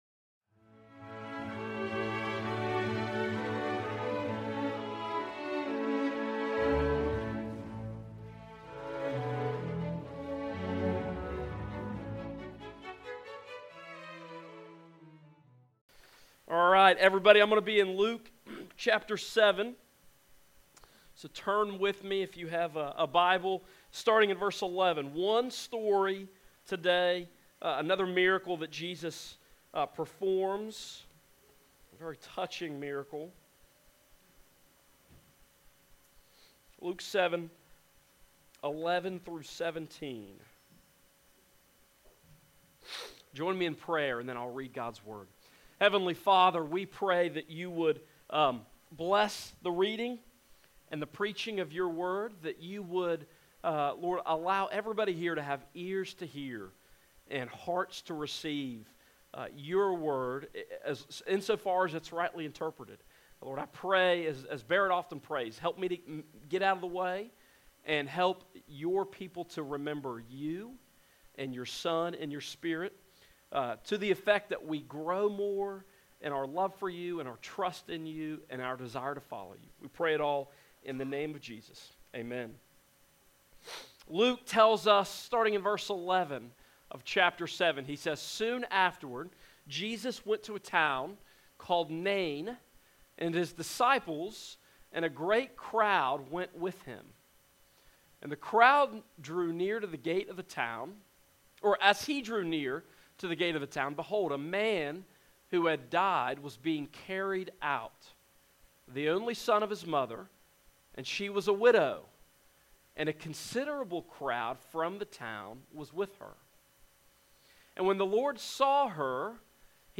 Service Type: Morning Service
Sermon-Intro_Joined-3.mp3